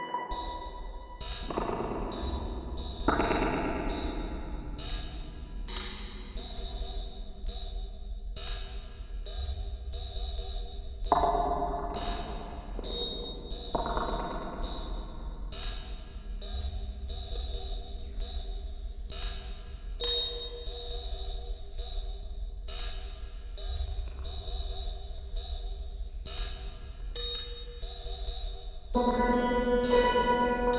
experimento_reverb_con_cubase.ogg